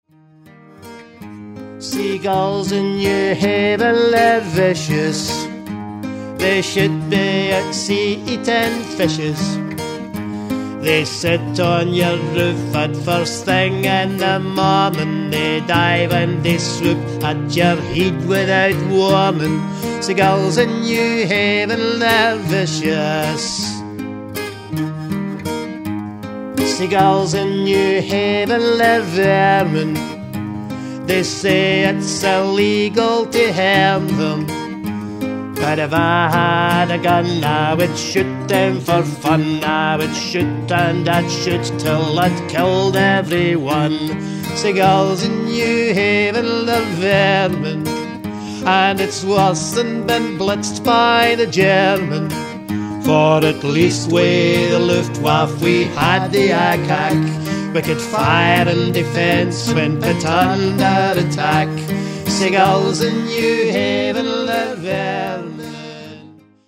vocal, guitar, bouzouki, harmonica and 5 string banjo
fiddle
bass guitar and keyboards
drum-kit